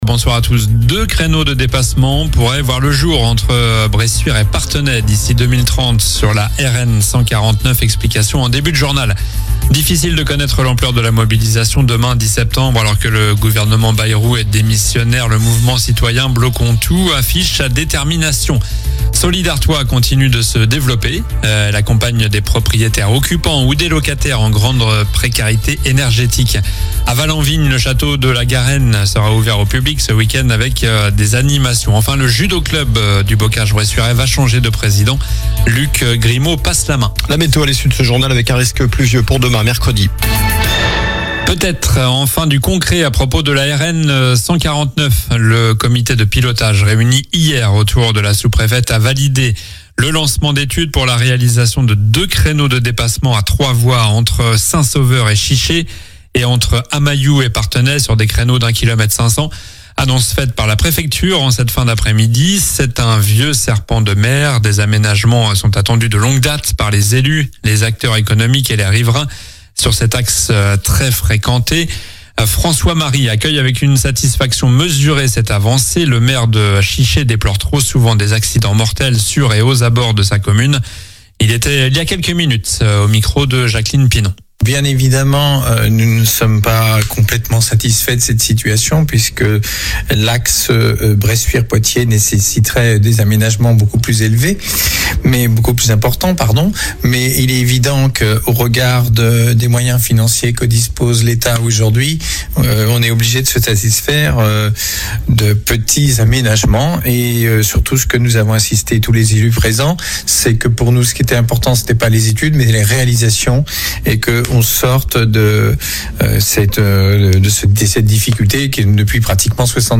Journal du mardi 09 septembre (soir)